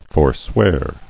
[for·swear]